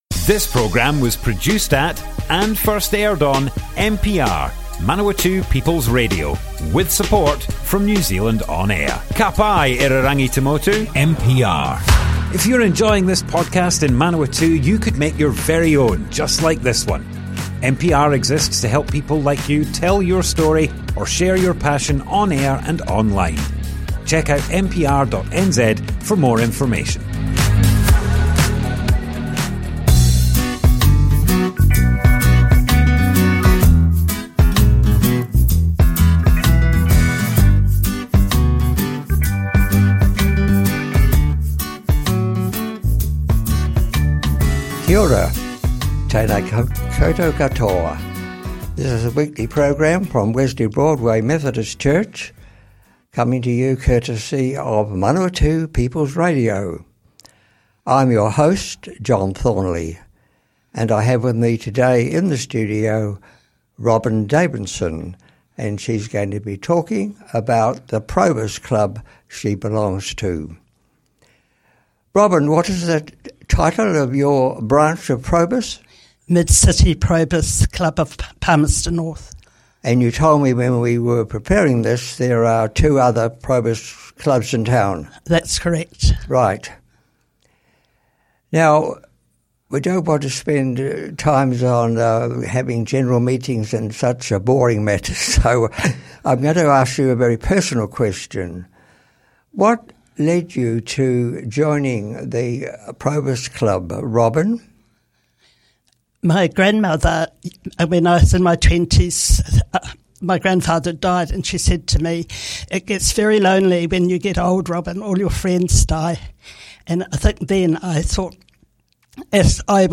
There's church news, guests, and a variety of music - especially songs by New Zealanders and contemporary music that expresses a spiritual truth for Aotearoa today.